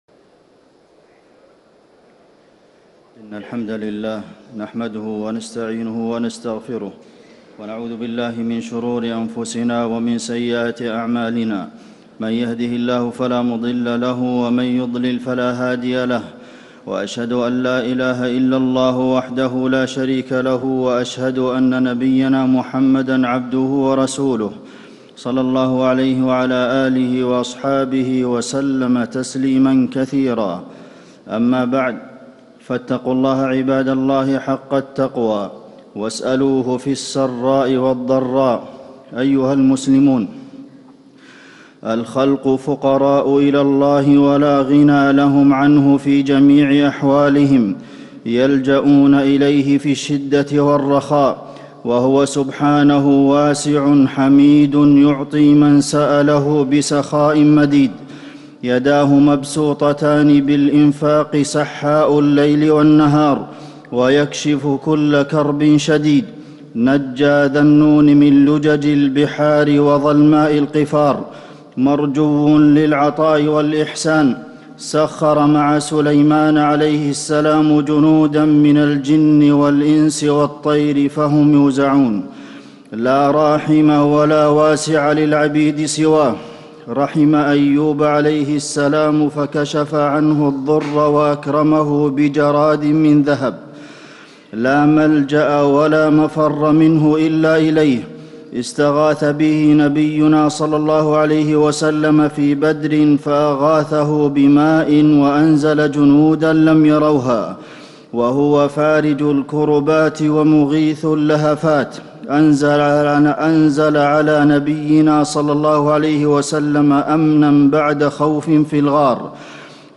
خطبة الاستسقاء - المدينة- الشيخ عبدالمحسن القاسم
تاريخ النشر ١٠ صفر ١٤٣٨ هـ المكان: المسجد النبوي الشيخ: فضيلة الشيخ د. عبدالمحسن بن محمد القاسم فضيلة الشيخ د. عبدالمحسن بن محمد القاسم خطبة الاستسقاء - المدينة- الشيخ عبدالمحسن القاسم